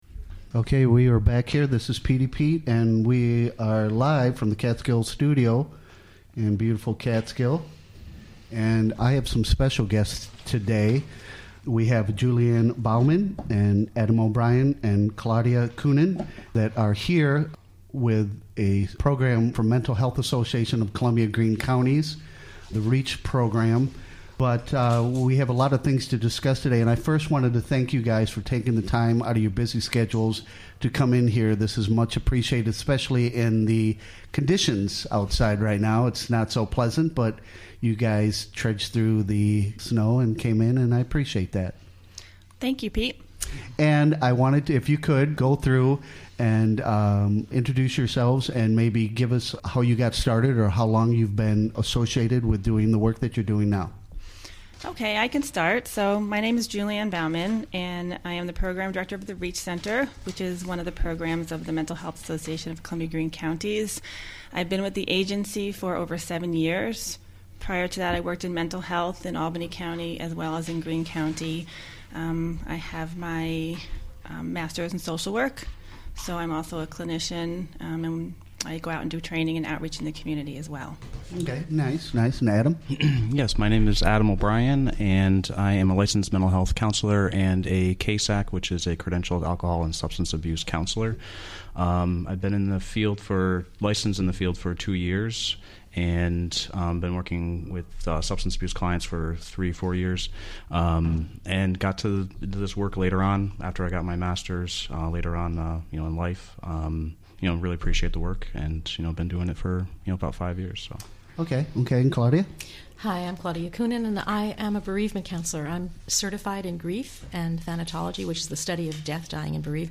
Recorded during the WGXC Morning Show.